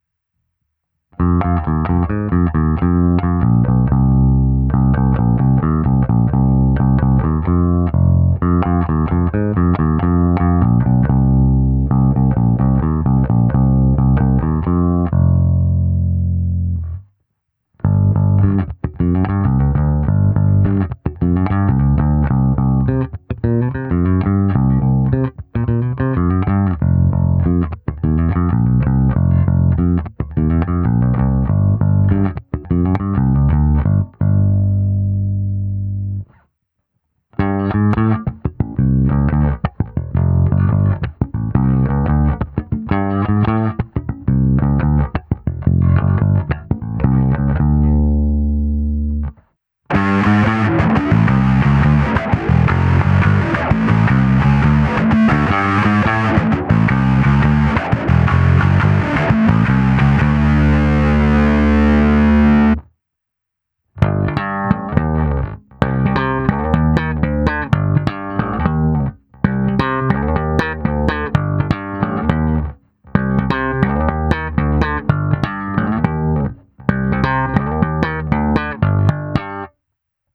Nahrávka se simulací aparátu, kde bylo použito i zkreslení a hra slapem, přičemž jsem použil zvuk, který jsem si oblíbil. Tónové clony jsou opět stažené cca o 1/3, přepínač krkového snímače v dolní poloze, přepínač kobylkového snímače naopak v horní poloze, jinak stejný poměr obou snímačů.